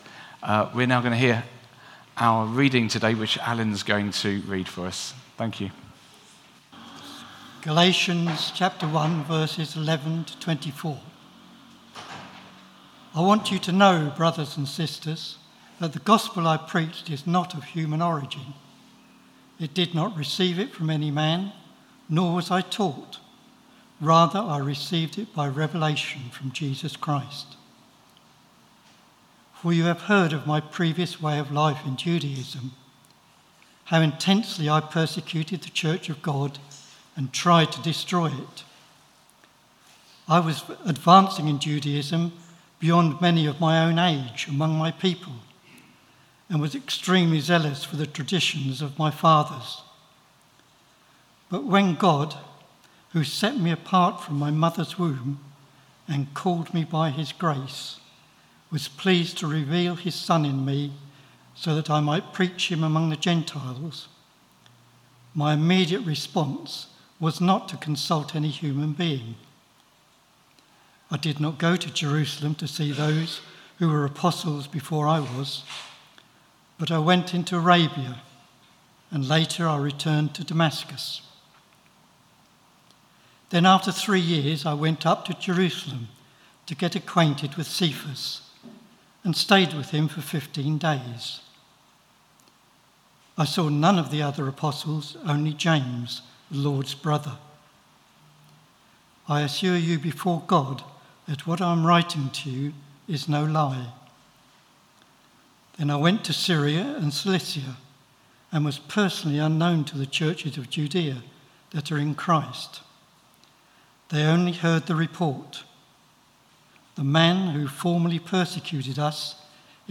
Sermon 25th June 2023 11am gathering
We have recorded our talk in case you missed it or want to listen again.